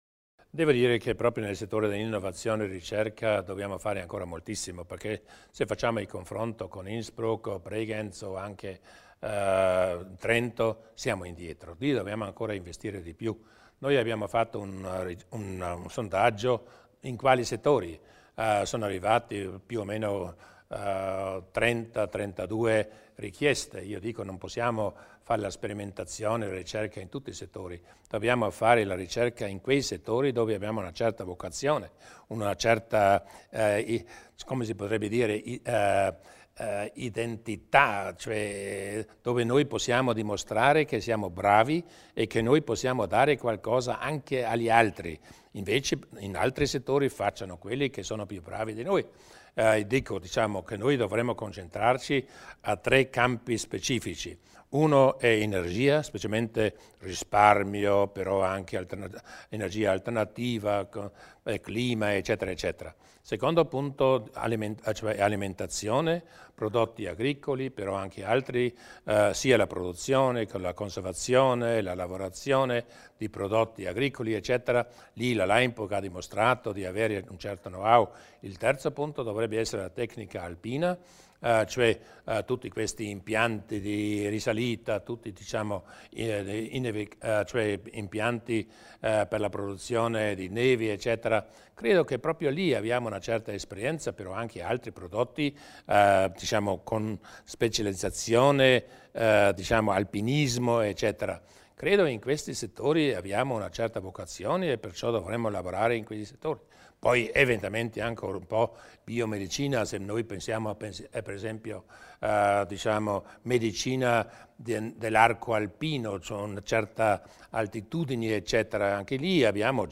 Il Presidente Durnwalder spiega gli obiettivi in tema di innovazione e ricerca